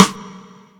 taiko-normal-hitwhistle.ogg